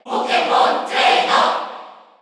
Category: Crowd cheers (SSBU) You cannot overwrite this file.
Pokémon_Trainer_Cheer_Korean_SSBU.ogg